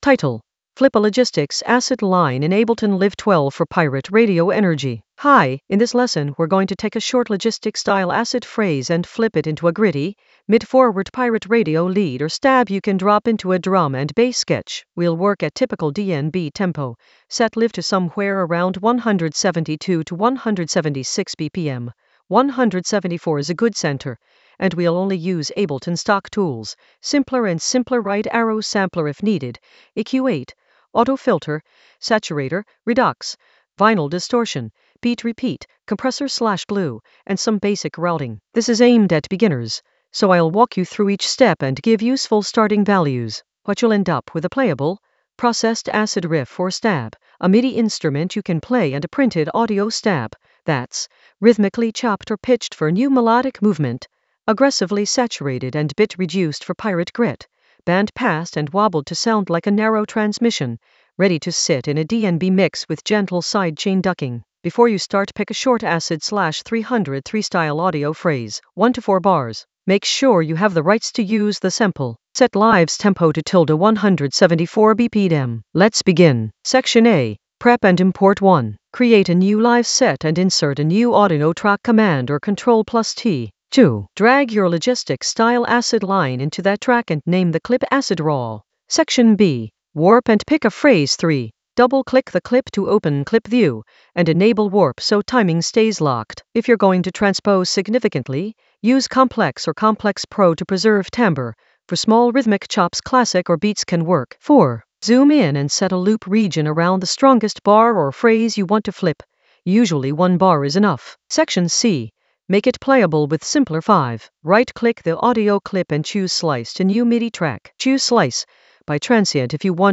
An AI-generated beginner Ableton lesson focused on Flip a Logistics acid line in Ableton Live 12 for pirate-radio energy in the Sampling area of drum and bass production.
Narrated lesson audio
The voice track includes the tutorial plus extra teacher commentary.